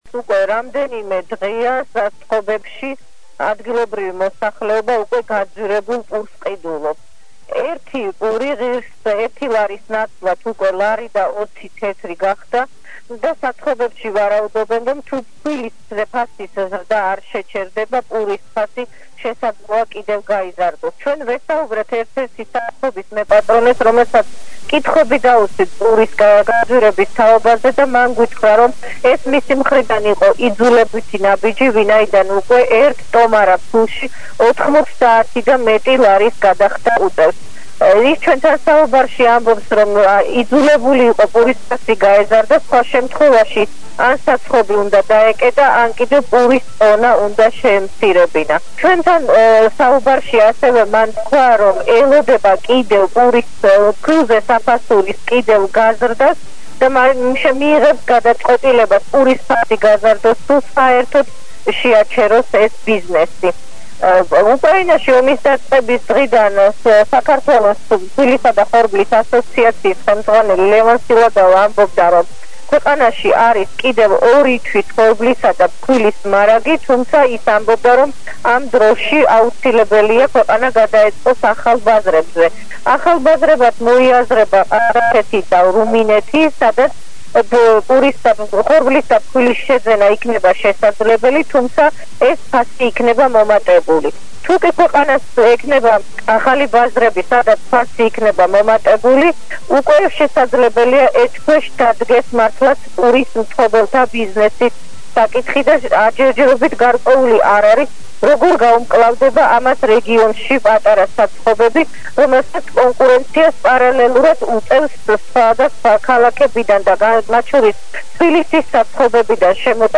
ჩართვას ახალ ამბებში